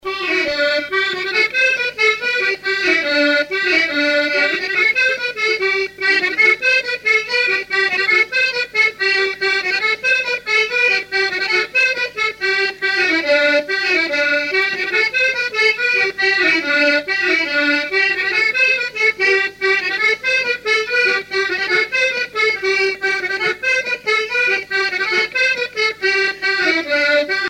Chants brefs - A danser
danse : branle : grenoïe
musique à danser à l'accordéon diatonique
Pièce musicale inédite